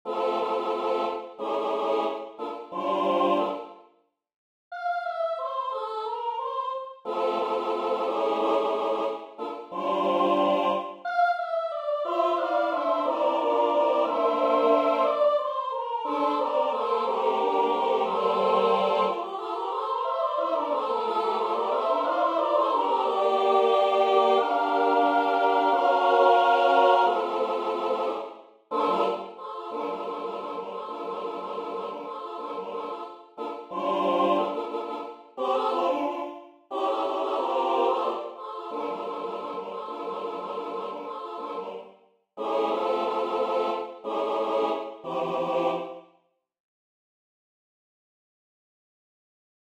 SSAA
(a capella)